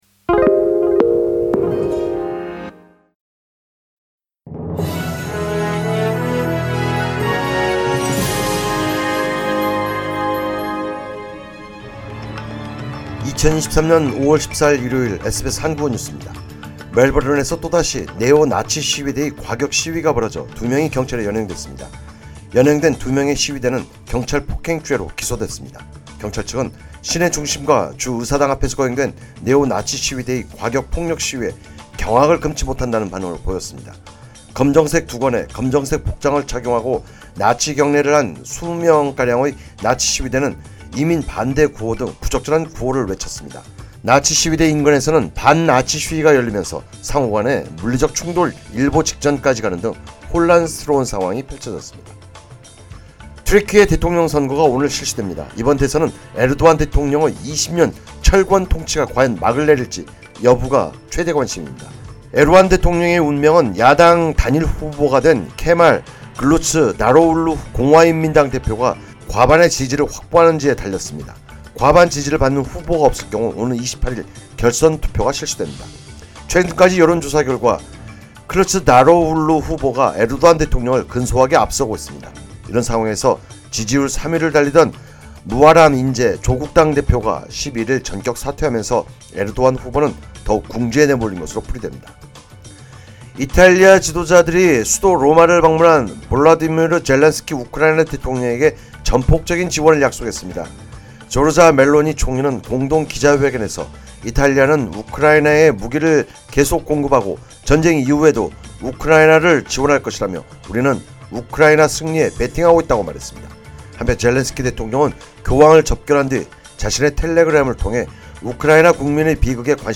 2023년 5월 14일 일요일 SBS 한국어 뉴스입니다.